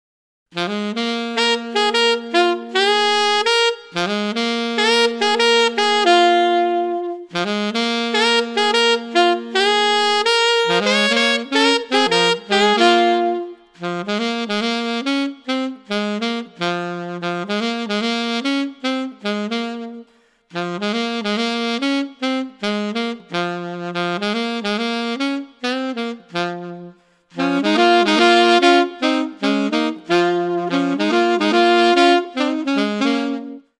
2 Saxophones